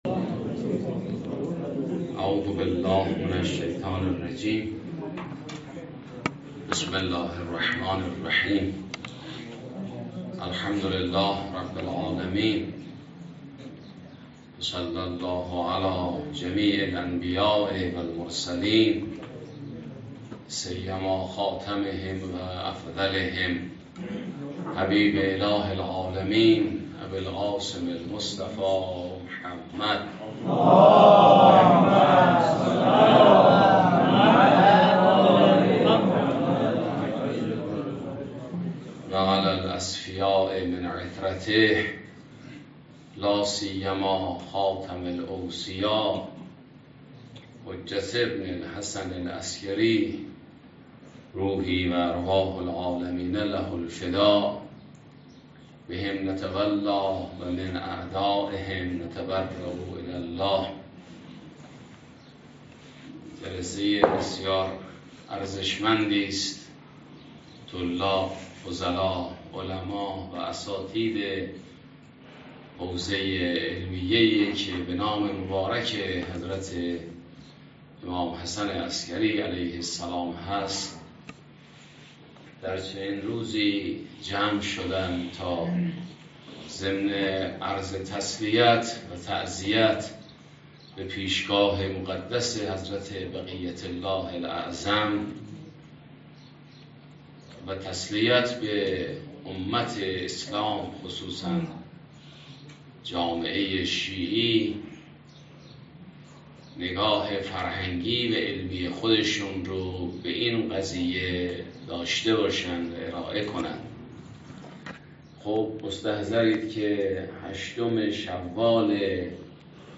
مراسم عزاداری به مناسبت 8شوال سالروز تخریب قبور ائمه بقیع علیهم السلام